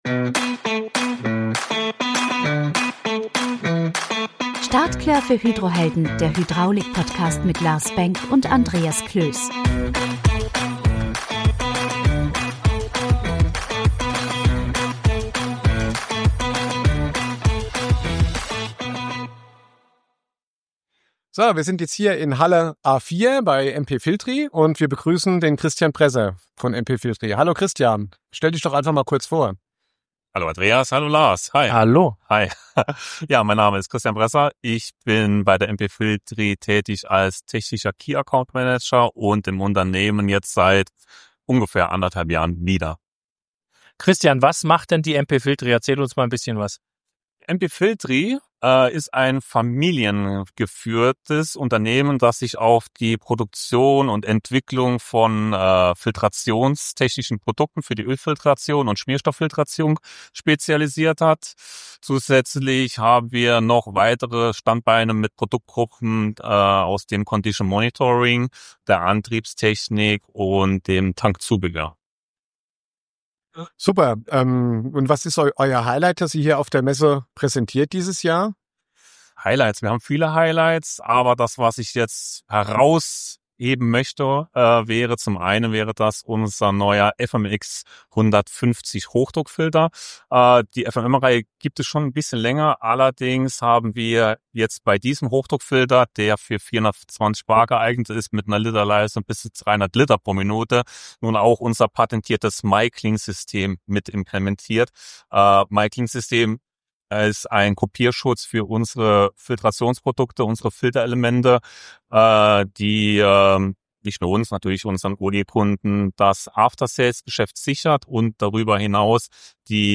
Beschreibung vor 1 Jahr In Teil 2 unseres Messe-Specials von der Bauma 2025 nehmen wir euch wieder mit auf einen Rundgang durch die Hallen der Hydraulik-Welt.
Eine Folge voller Energie, Technik und echter Messeatmosphäre – reinhören lohnt sich!